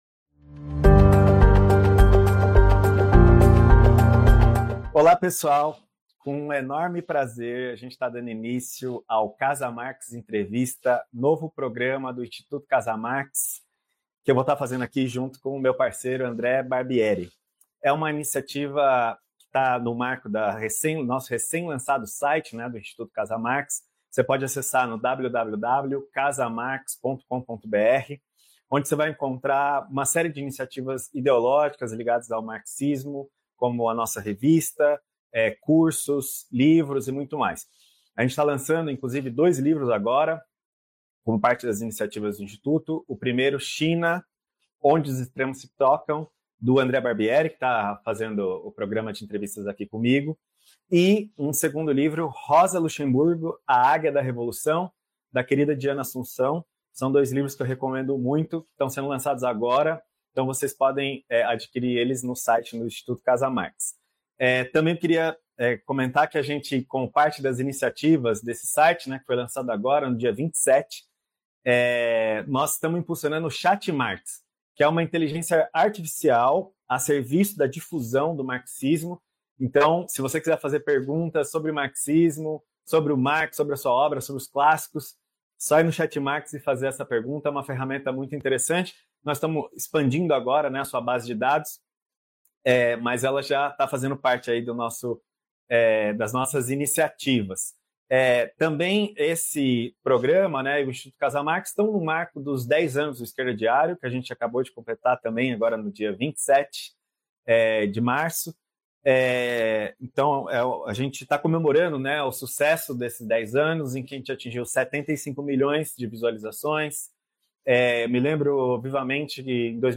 entrevista exclusiva